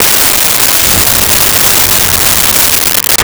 Car Start 02
Car Start 02.wav